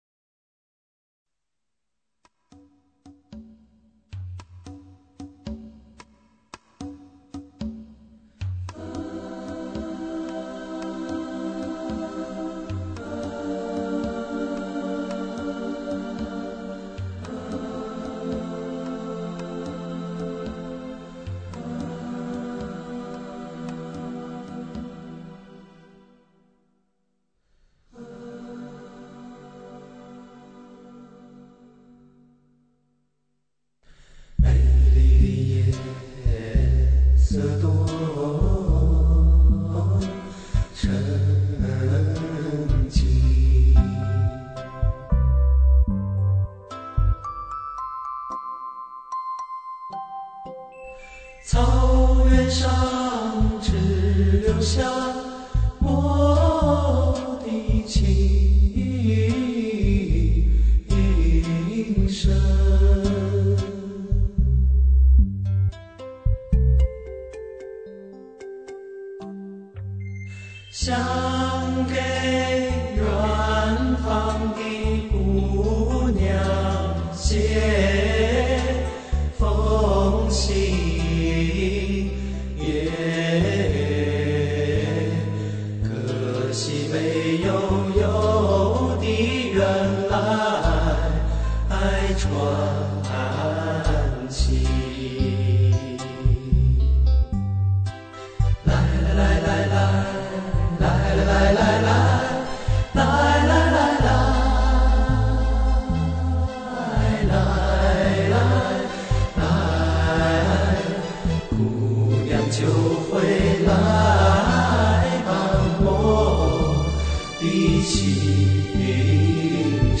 基本上都上轻唱的，很不错了哦，原版的CD很多人都用来试音的！